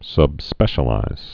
(sŭbspĕshə-līz)